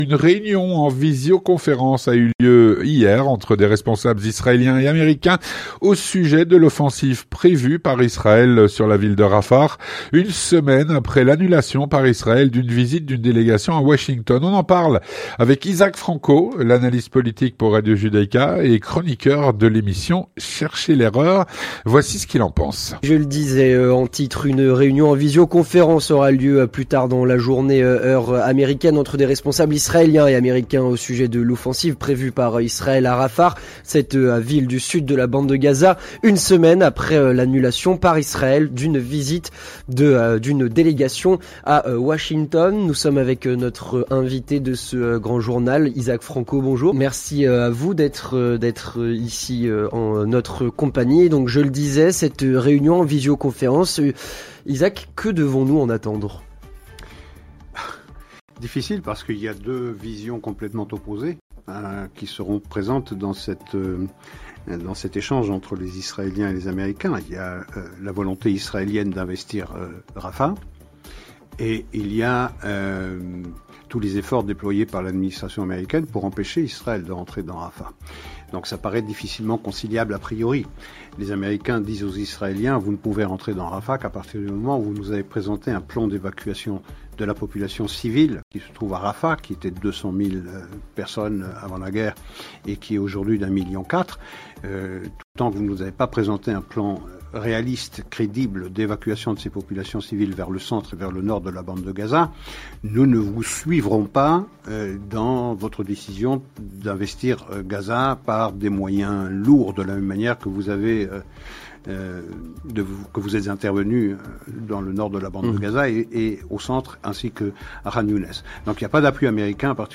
L'entretien du 18H - Une réunion en visioconférence a eu lieu lundi entre des responsables israéliens et américains au sujet de l'offensive prévue par Israël à Rafah.